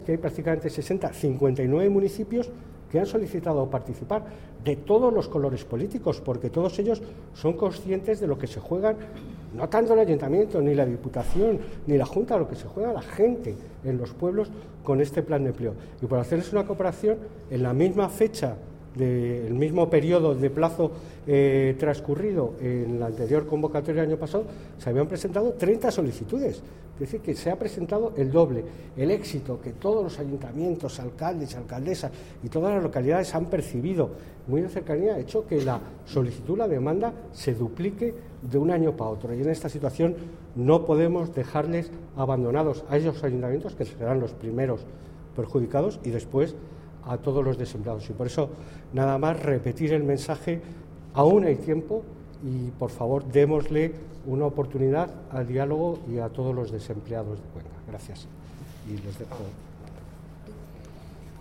francisco_rueda_viceconsejero_oportunidad_desempleados_cuenca.mp3